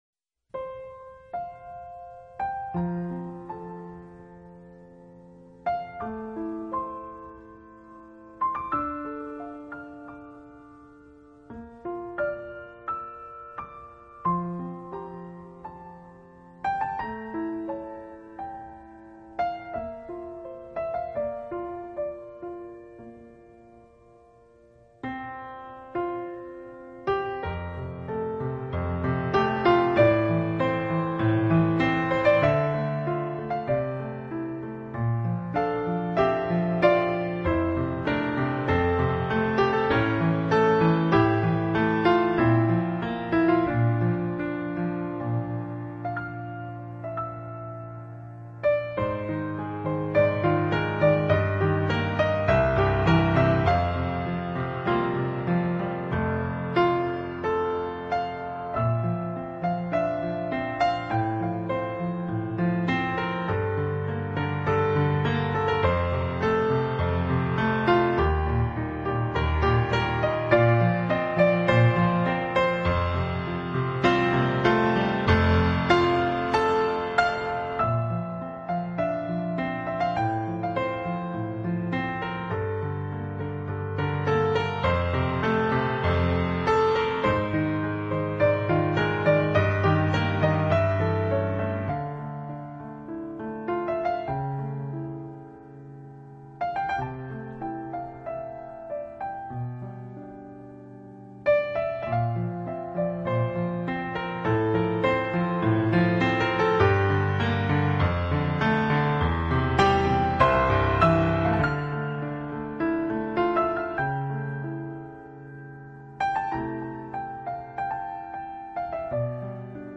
音乐类型：New Age